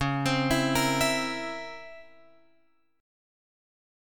C# Minor Major 13th